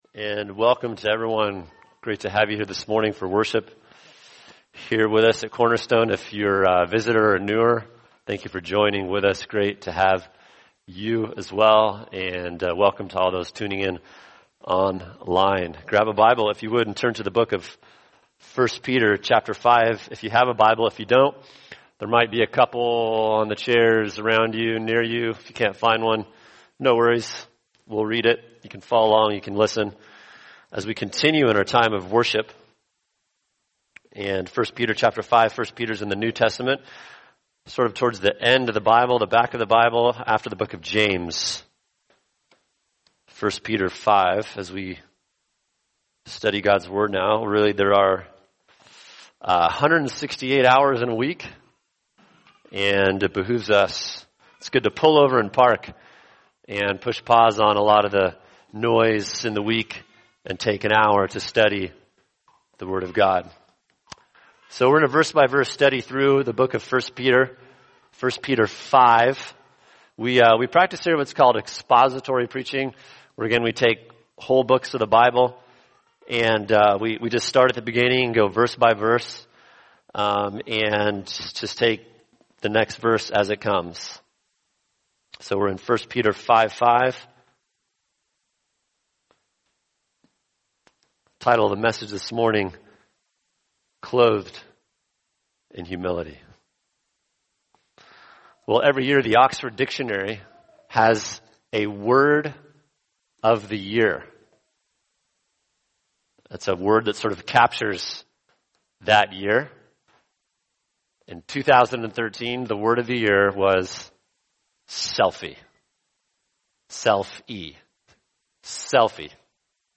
[sermon] 1 Peter 5:5 Clothed With Humility | Cornerstone Church - Jackson Hole